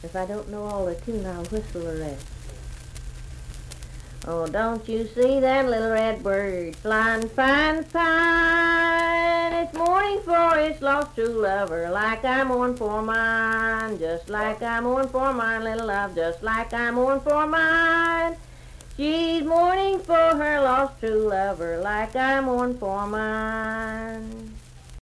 Unaccompanied vocal music
Verse-refrain 2(4w/R).
Voice (sung)